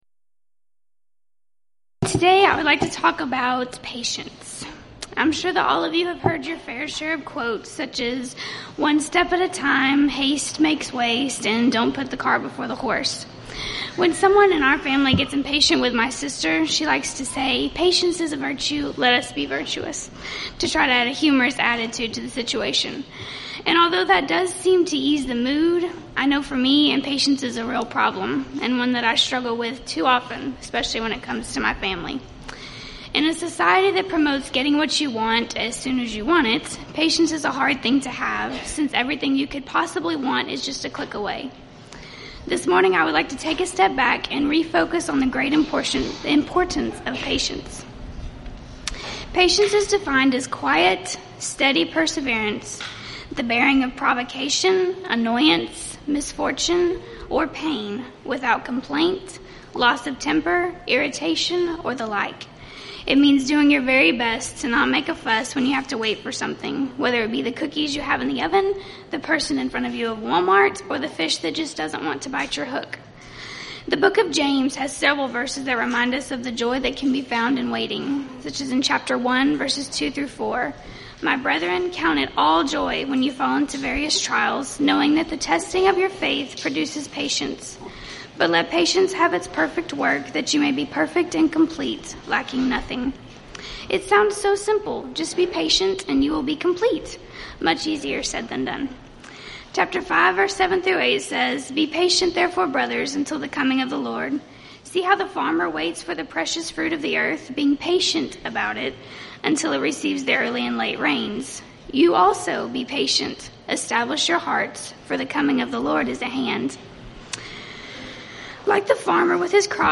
Event: 3rd Annual Texas Ladies in Christ Retreat Theme/Title: Studies in I Peter